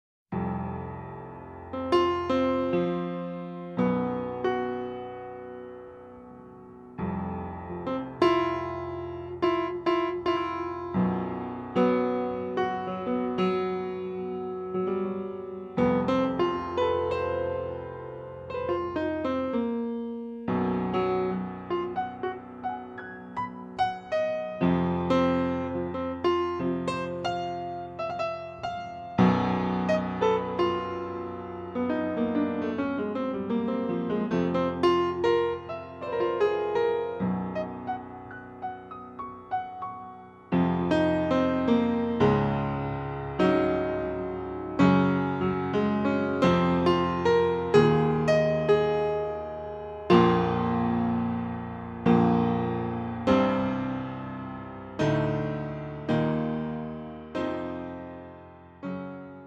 Les musiciens du quintet:
Les voici écourtés et dans l'ordre du CD.